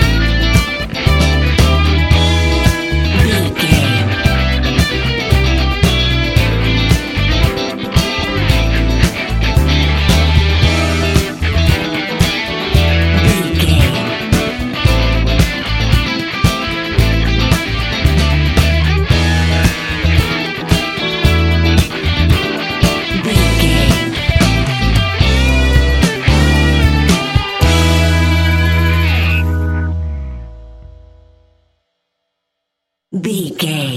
Ionian/Major
A♭
house
electro dance
synths
techno
trance